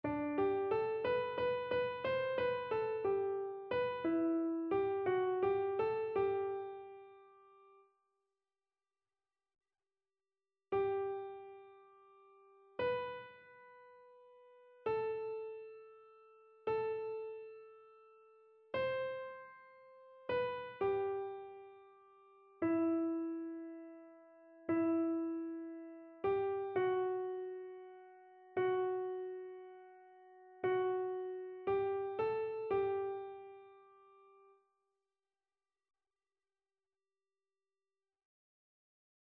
SopranoAlto
annee-abc-fetes-et-solennites-dedicace-des-eglises-psaume-83-soprano.mp3